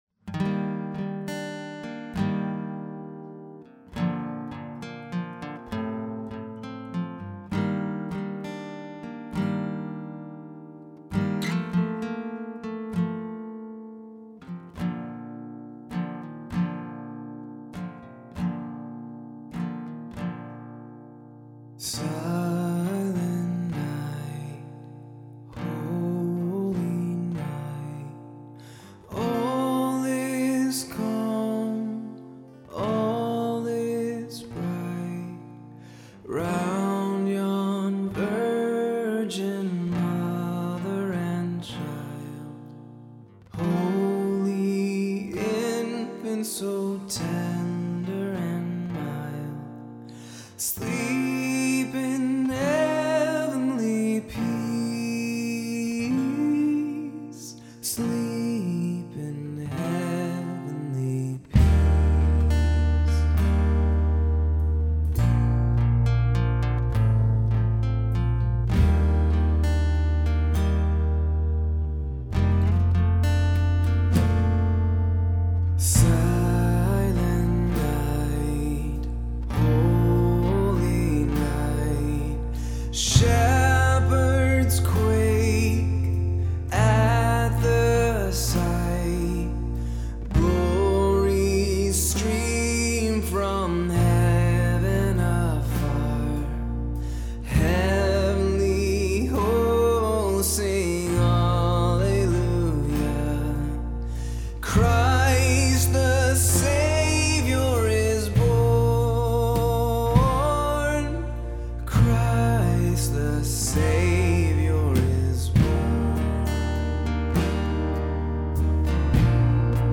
There are only 3 channels of drums (kick and two overheads). There is a bass going direct, an electric guitar with the amp run into a separate room and captured with an SM57, 2 channels for acoustic guitar (1 going direct and 1 large diaphragm condenser), and an SM57 for lead vocals.
Because of the way that the song was recorded, there was a lot of drum bleed in the acoustic guitar microphone and vocal microphone.
The lead vocals ended up being re-recorded at a later date since there was so much drum bleed. Other parts were added to the song, as well.